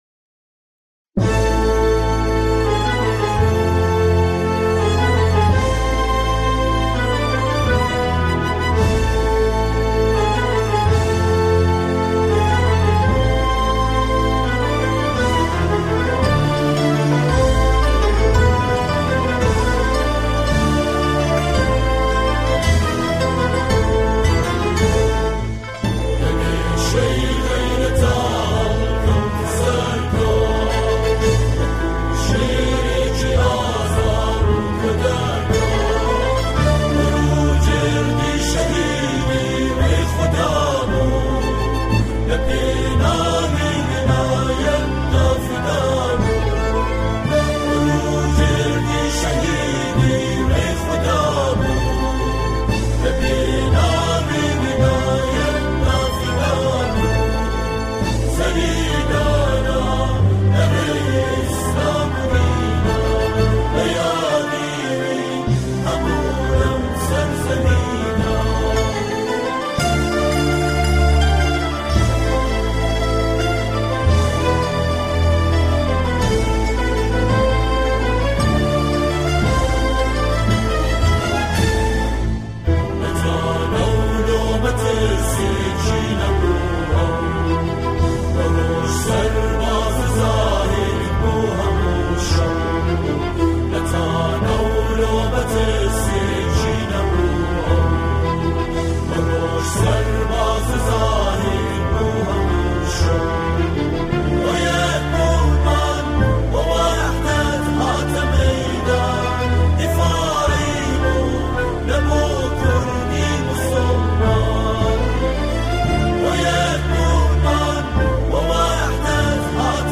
همخوانی
با صدای گروهی از جمعخوانان